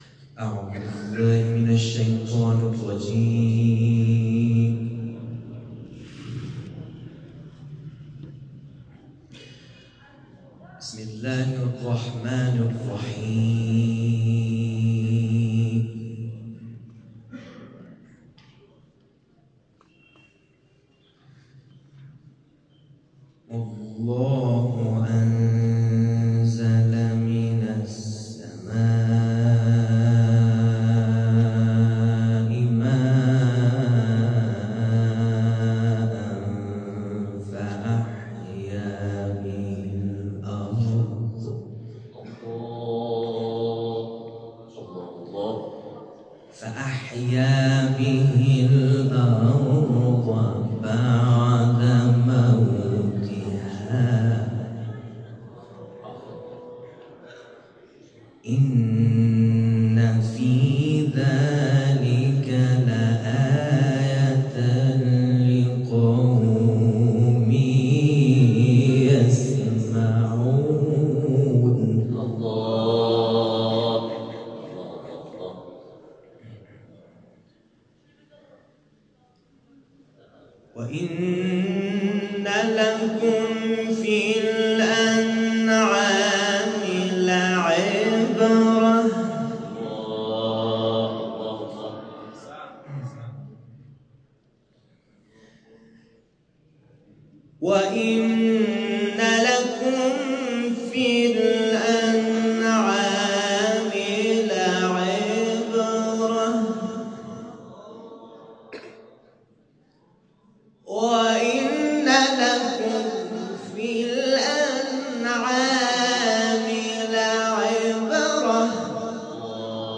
در محفل قرآنی مسجد جامع این شهر اجرا شده است
تلاوت